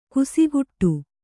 ♪ kusiguṭṭu